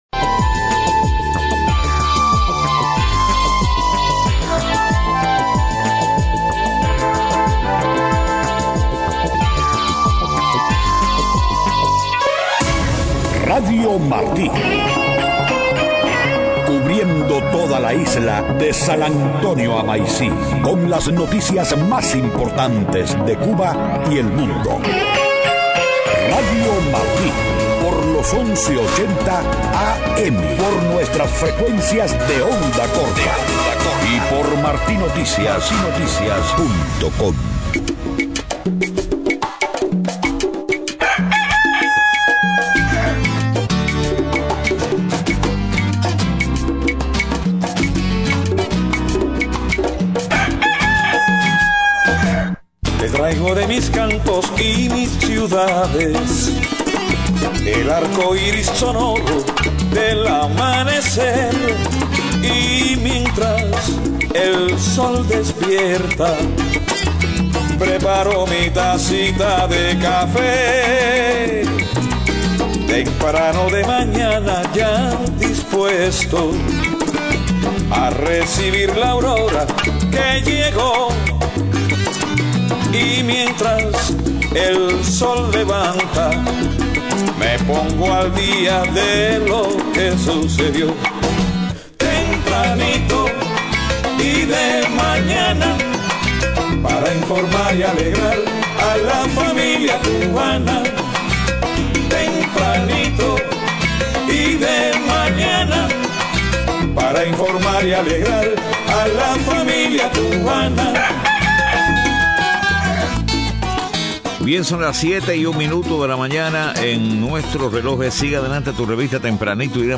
7:00 a.m Noticias: UNPACU denuncia amenazas por actividades cívicas. Cancilleres de UNASUR acuerdan enviar ministros de Relaciones Exteriores a Venezuela.